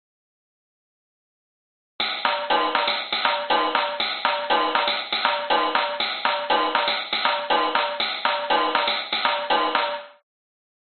玩具打击乐 " 玩具卡鼓卷2
描述：用3个不同的电容话筒录制的打击乐套件和循环，用Wavosaur编辑。
标签： 电子 填充 试剂盒 打击乐器 玩具
声道立体声